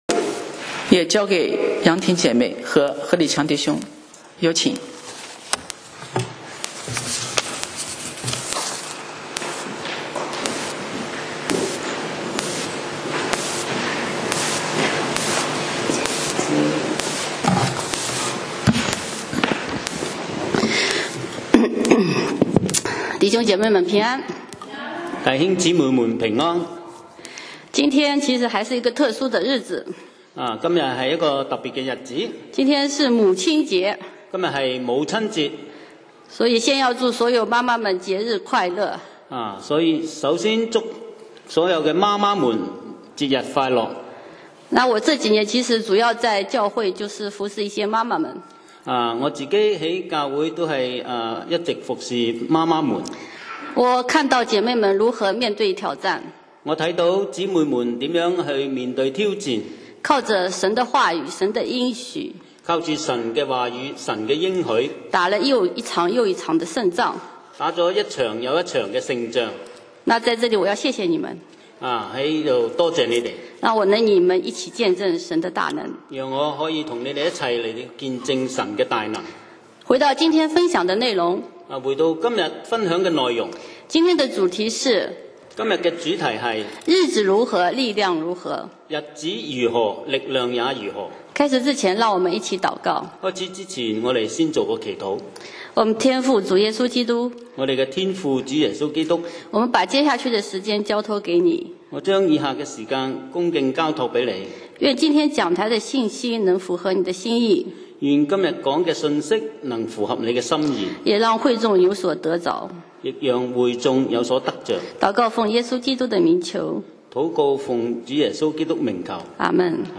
講道 Sermon 題目 Topic：日子如何，力量如何 經文 Verses： 申命记33:24-25, 约书亚记14:6-12. 24论亚设说，愿亚设享受多子的福乐，得他弟兄的喜悦，可以把脚蘸在油中。